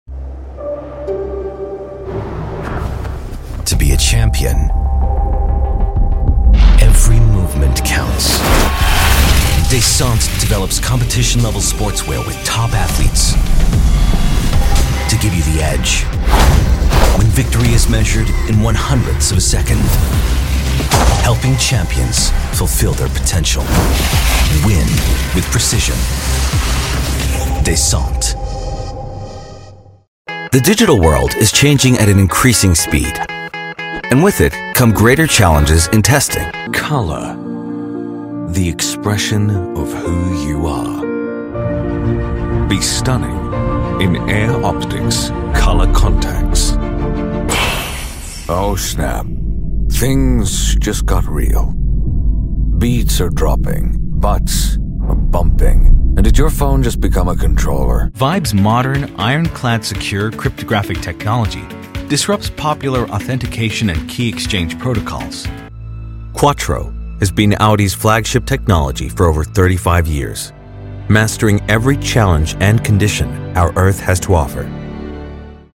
Neutral/Global/Transatlantic demo
Commercial (Werbung), Narrative, Station Voice
English NEUTRAL